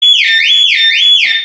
2007 Volvo Xc90 Alarm - Sound Effect Button